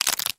Шум расколотого ореха